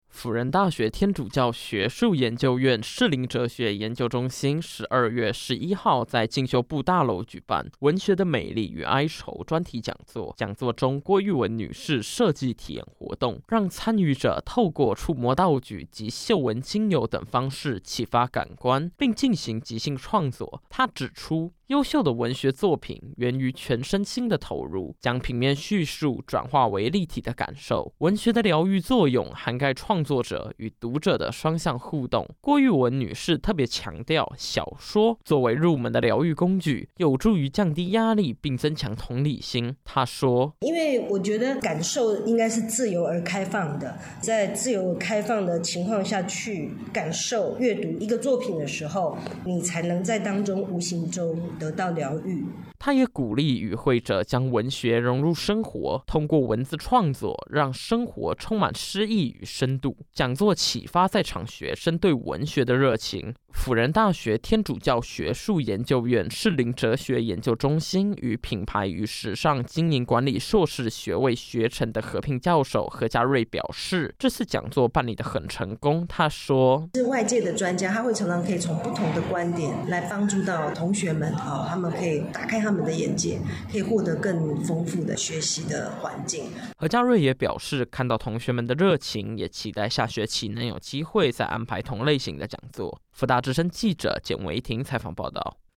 （輔大之聲記者